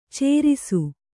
♪ cērisu